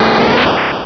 Cri de Coconfort dans Pokémon Rubis et Saphir.